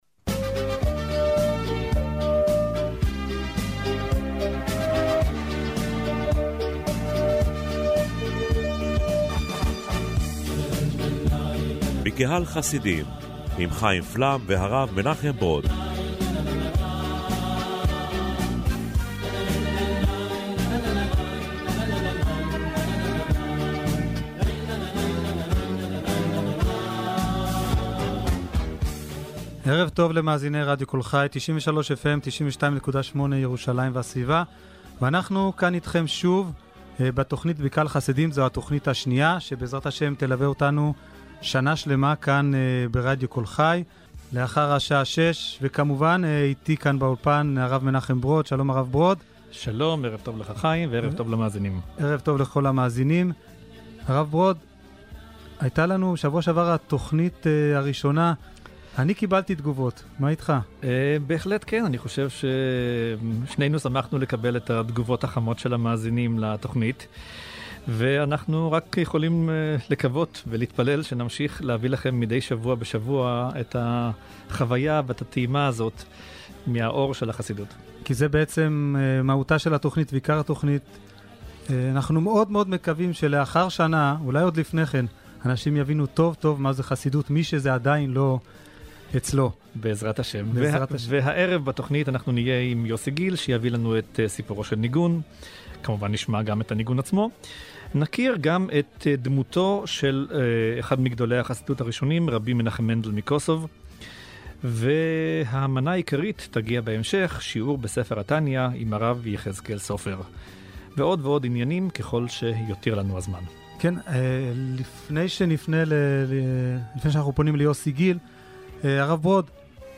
מזה בחודש משודרת תוכנית רדיו שבועית חדשה, העוסקת בחסידות לגווניה, שעלתה לרדיו קול חי, ובה שולב שיעור שבועי בספר התניא.